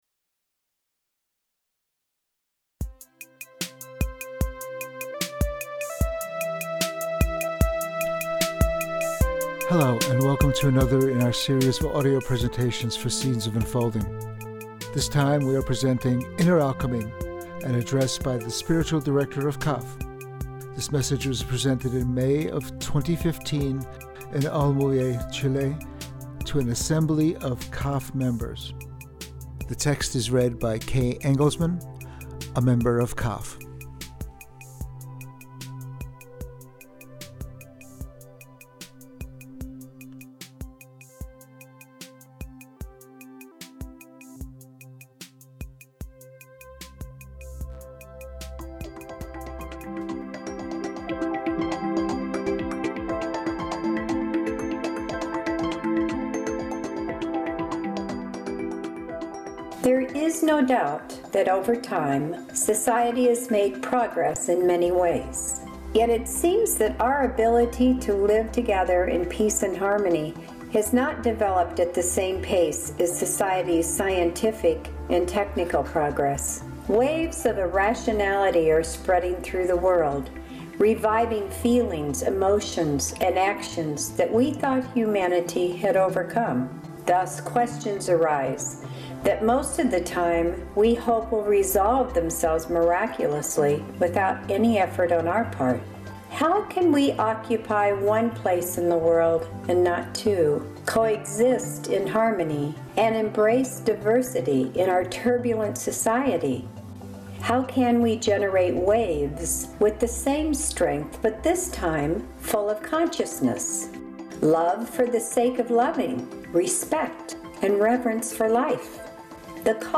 Though the address below was delivered to an assembly of members of Cafh in Olmué, Chile, in May 2015, Seeds believes that everyone who is dedicated to spiritual unfolding for their own good and the good of all human beings will find it inspiring and helpful.